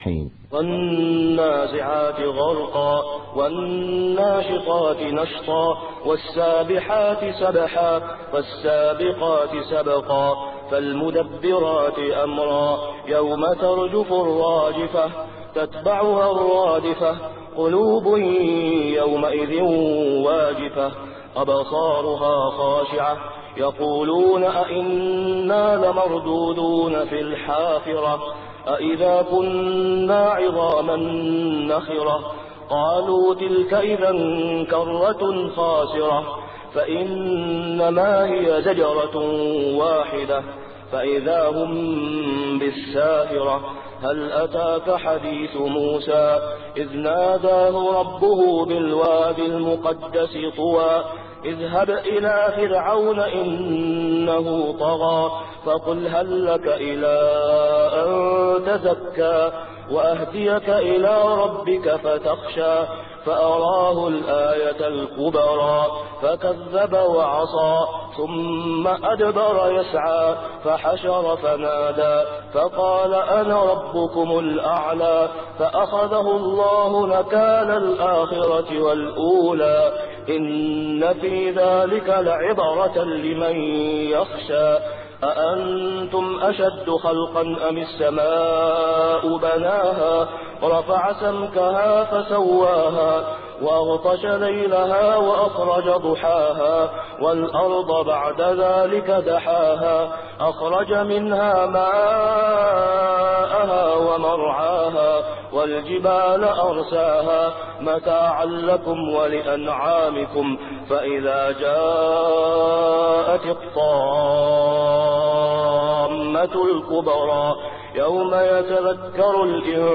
صلاة الفجر عام 1423هـ | سورة النازعات كاملة | > 1423 🕋 > الفروض - تلاوات الحرمين